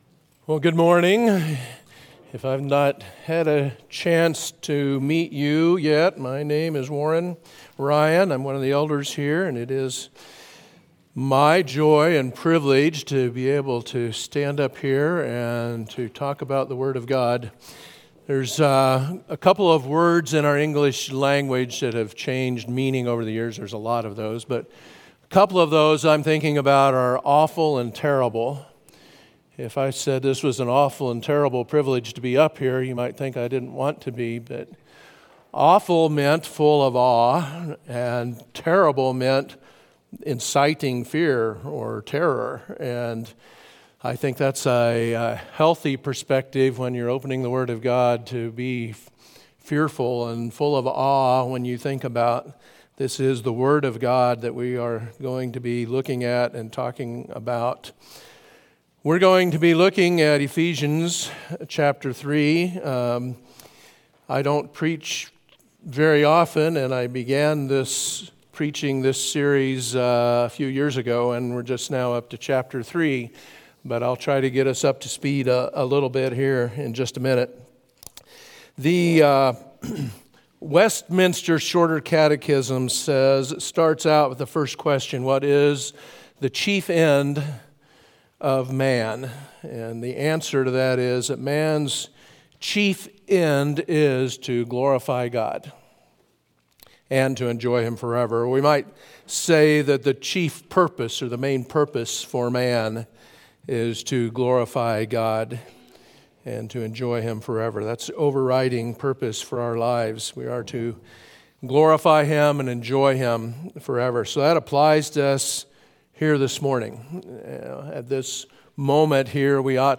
Sermons – Wichita Bible Church